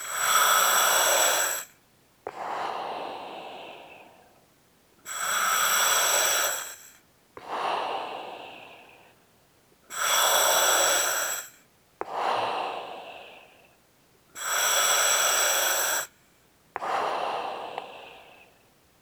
Index of /90_sSampleCDs/E-MU Producer Series Vol. 3 – Hollywood Sound Effects/Water/Scuba Breathing